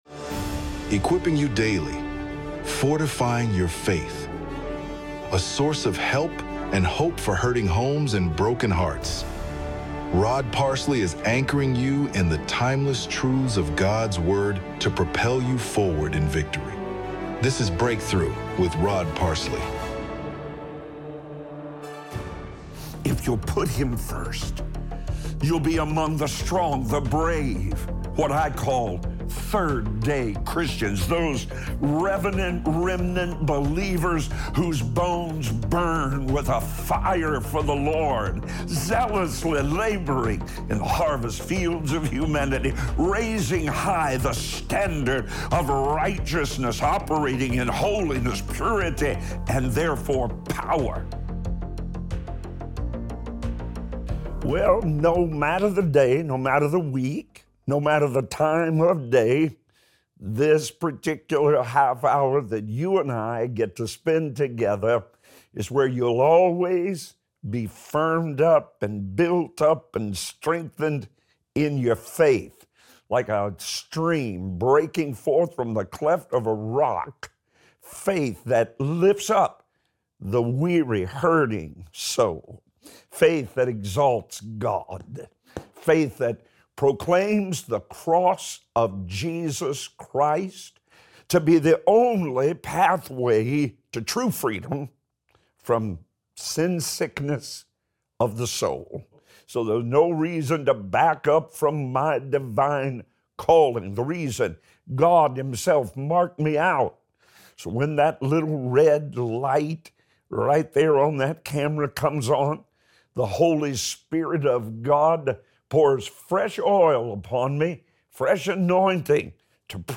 Audio only from the daily television program Breakthrough